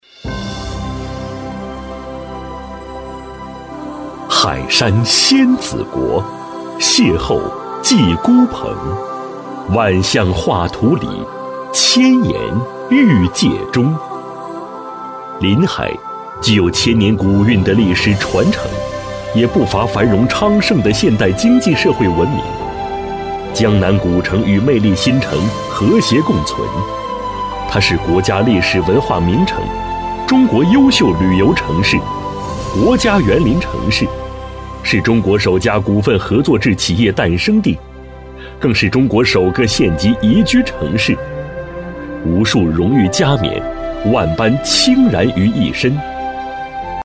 医院配音